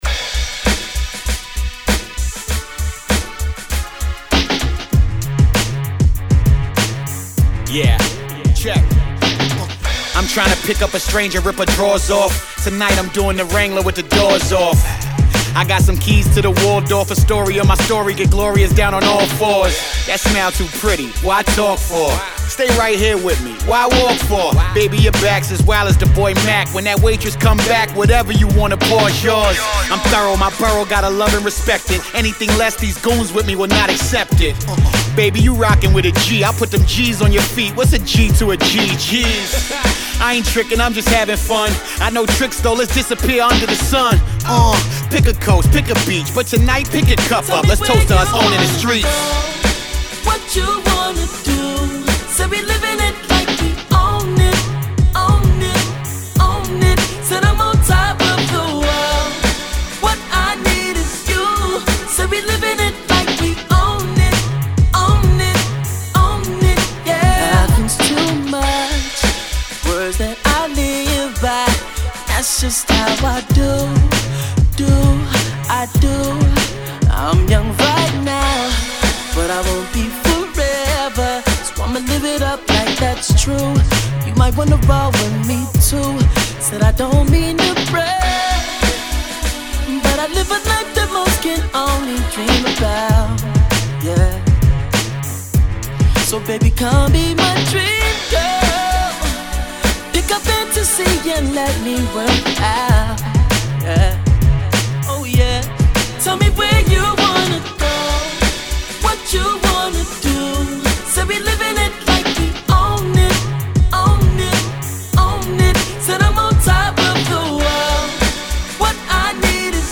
Keeping with his heavy Hip-Hop influences